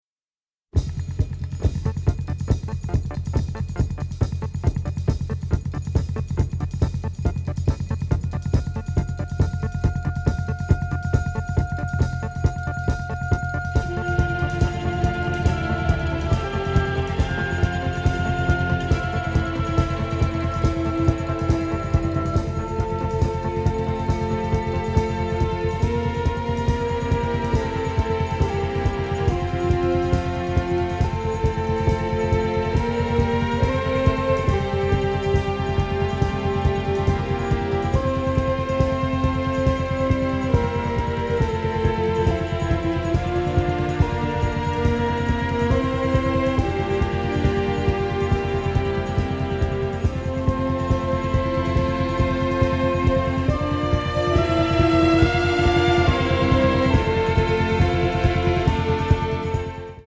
romantic, innovative, masterful score
a Moog synthesizer in its propulsive main theme